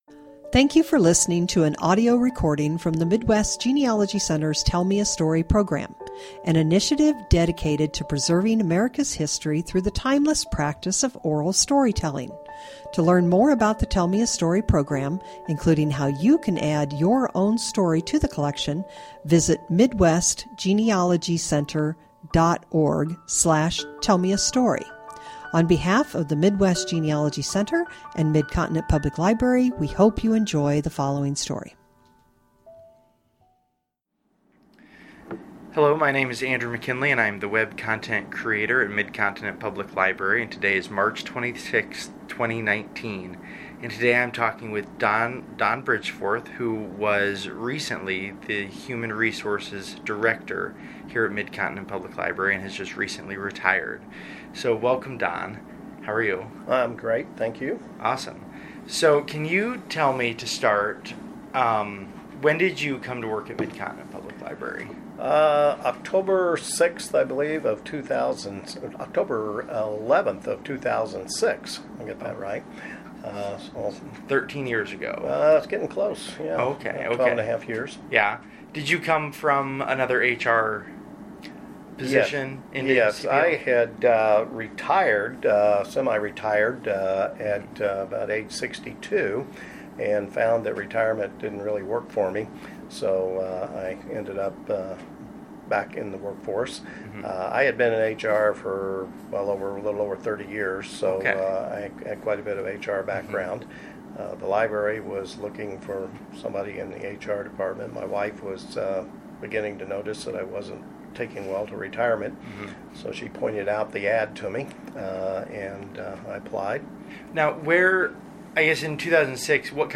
Interview
Stereo